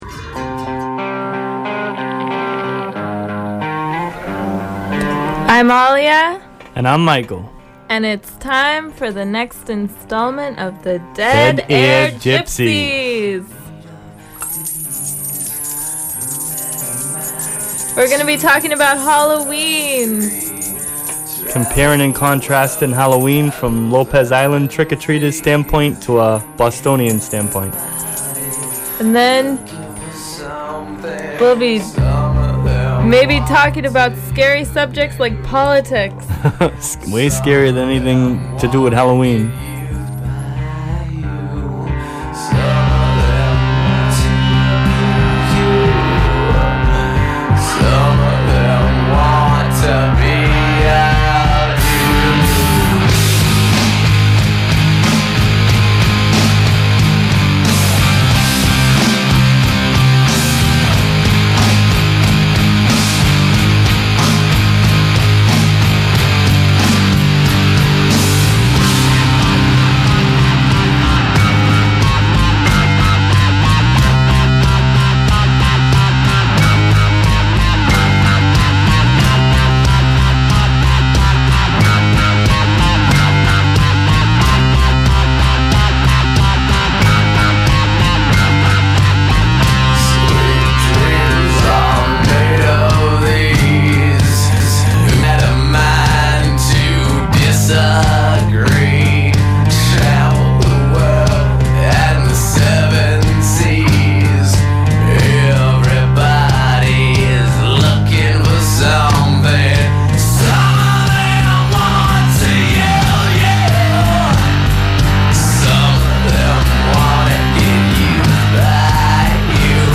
Note: This is a 50 minute audio piece of the interviews and music only and not the full show Gleanings in which it was incorporated into.